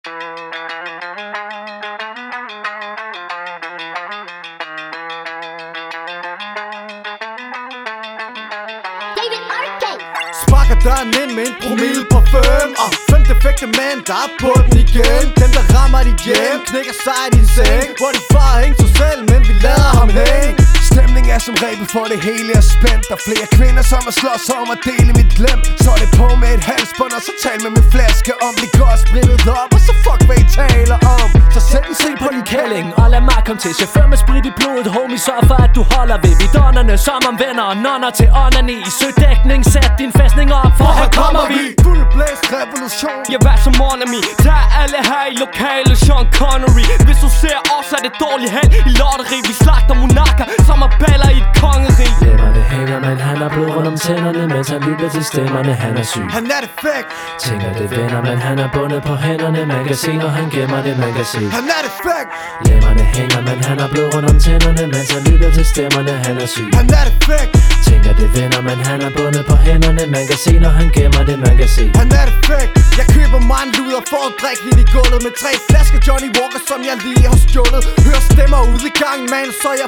• Hip hop